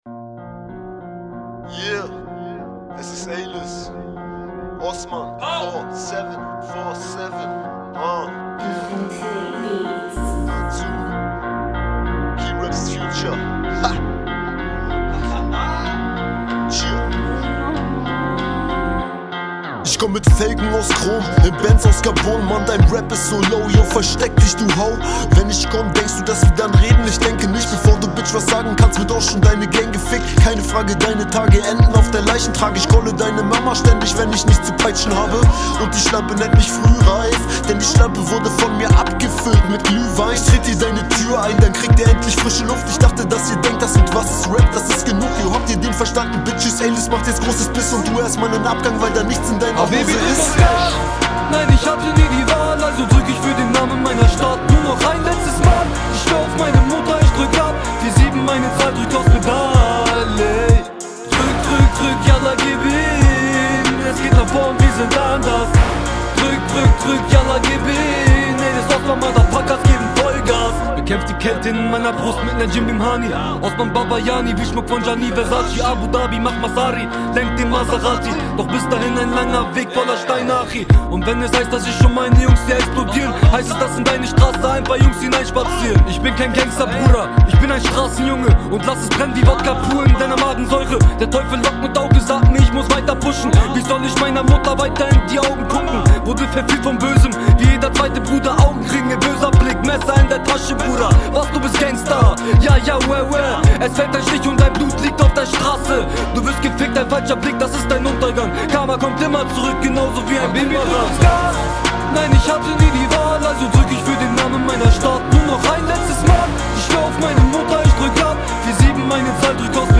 Rap/Newschool
So, ich hab auch einen Mix angefertigt. Die Aufnahmen waren OK. Deine Stimme ging klar, die von deinem Kollegen zischelt allerdings wie Sau.